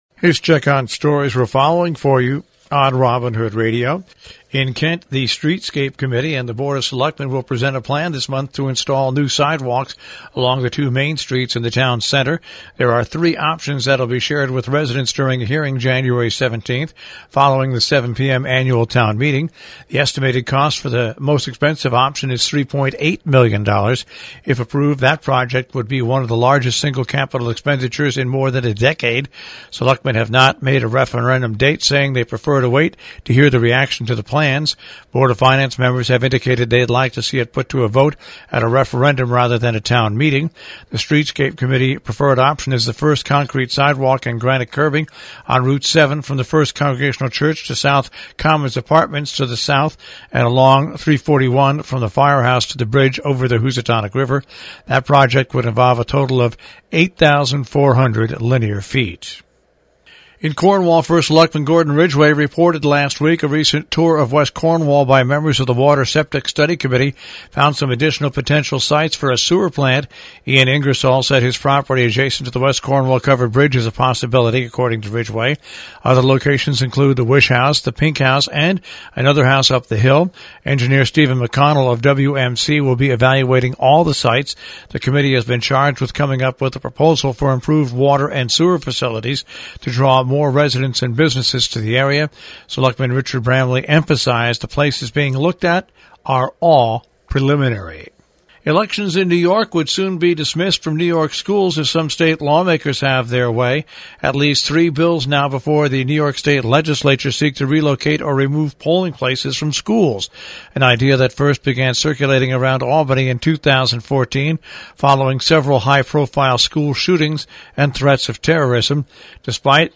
covers news and events in the Tri-State Region on The Breakfast club on Robin Hood radio